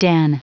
Prononciation du mot den en anglais (fichier audio)
Prononciation du mot : den